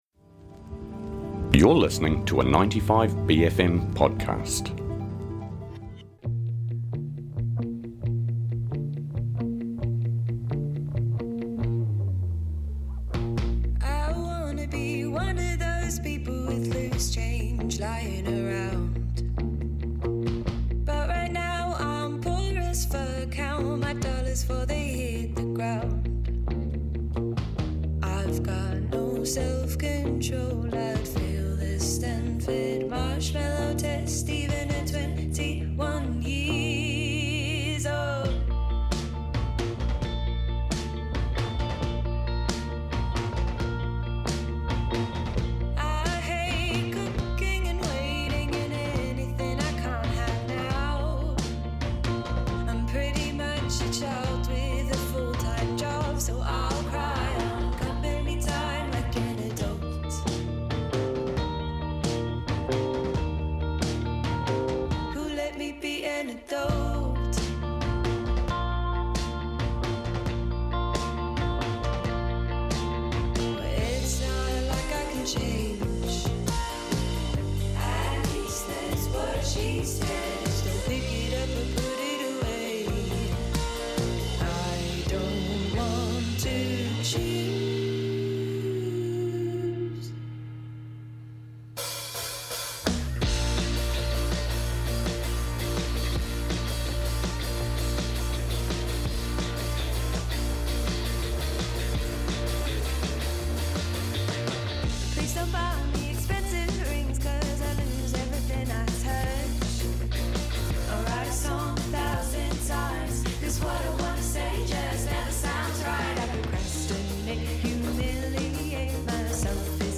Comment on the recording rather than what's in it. A simple feed of all the interviews from our many and varied special bFM Breakfast guests. up in the studio for a kōrero